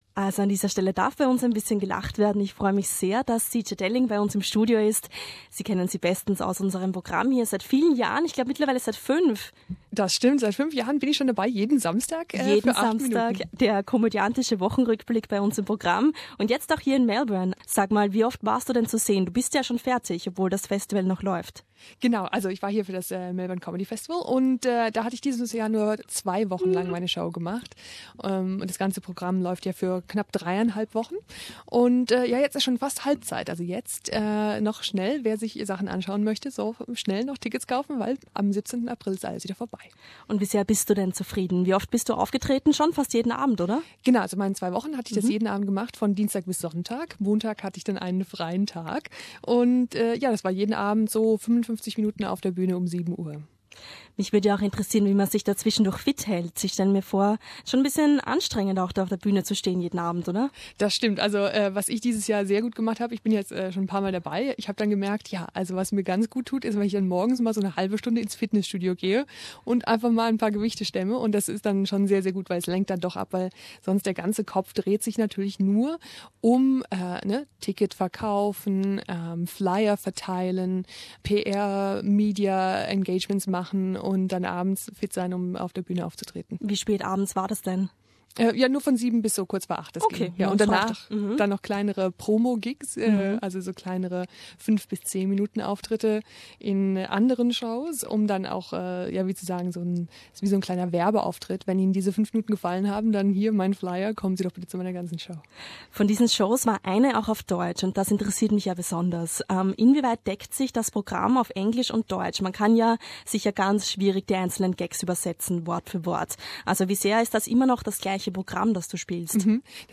After her engagement at the International Comedy Festival she found time for a visit at our studio.